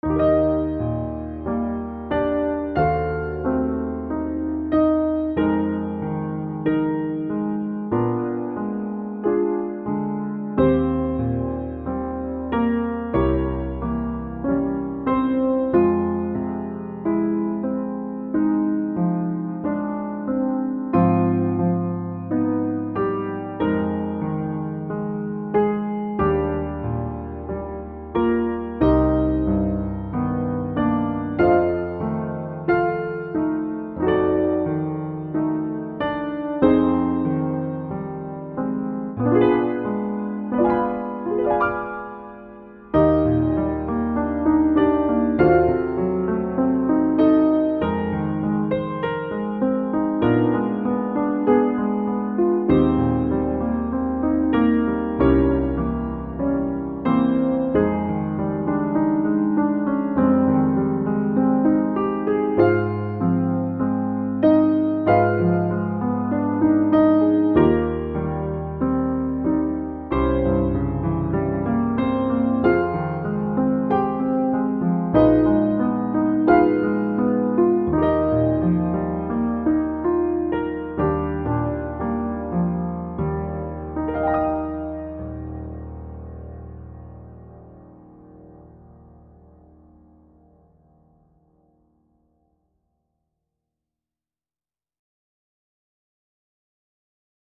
you played it in Eb